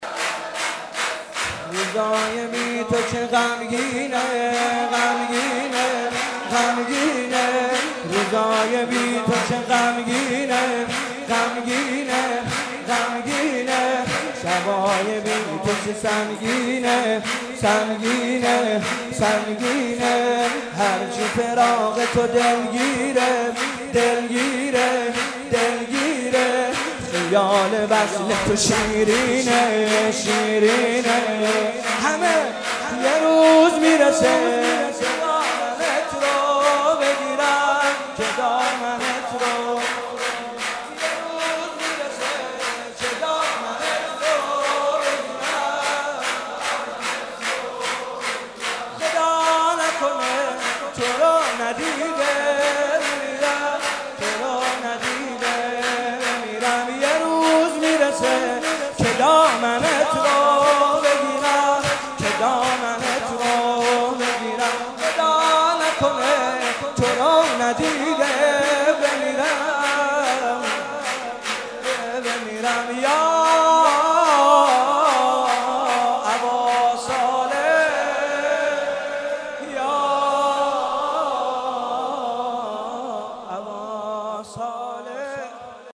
مناسبت : ولادت حضرت مهدی عج‌الله تعالی‌فرج‌الشریف
قالب : شور